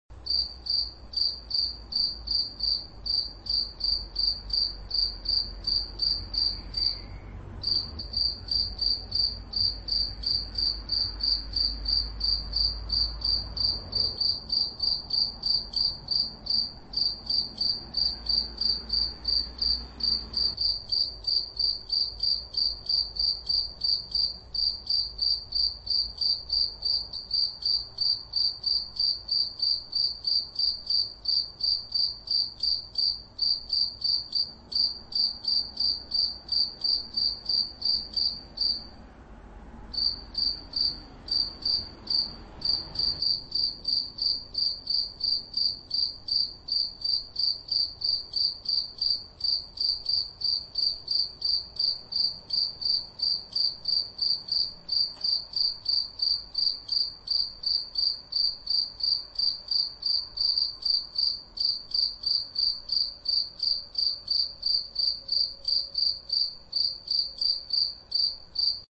Genre: Âm thanh tiếng động